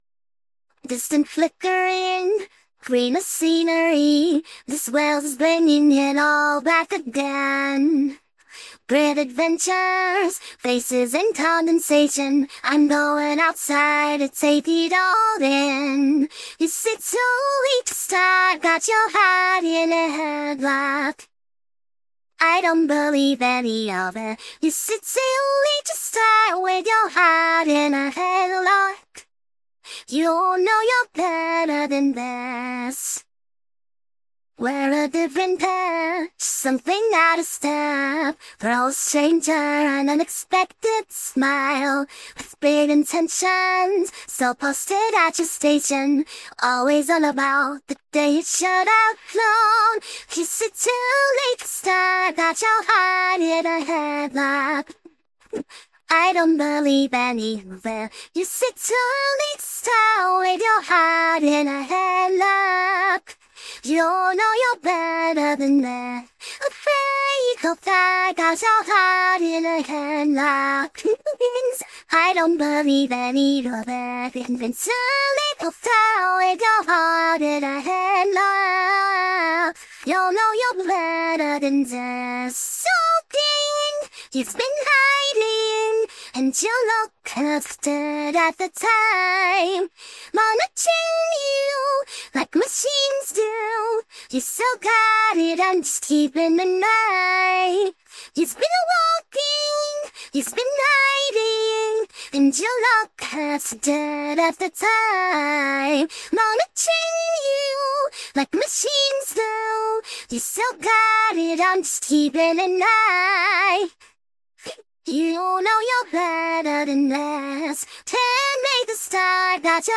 Nifty (Hazbin Hotel | Pilot) - (RVC V2 | RVMPE | Legacy Core Pretrain) - (160 Epochs) AI Voice Model
Nifty (Hazbin Hotel | Pilot) - (RVC V2 | RVMPE | Legacy Core Pretrain) - (160 Epochs)